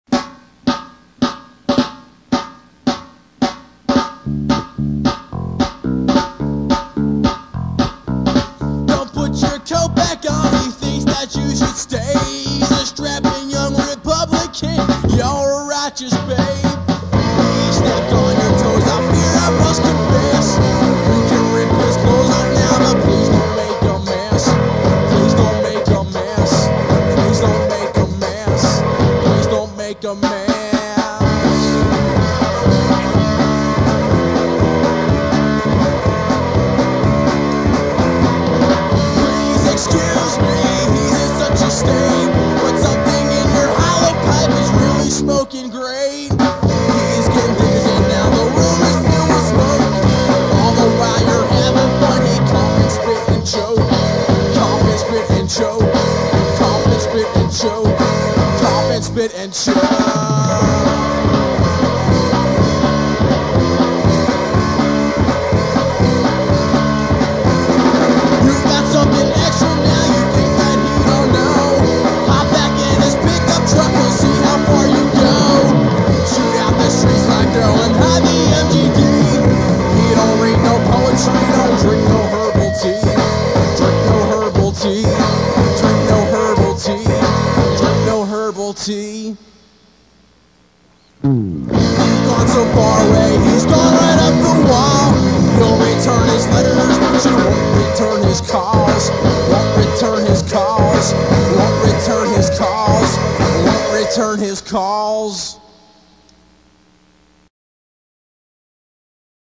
punk rock See all items with this value
pop punk See all items with this value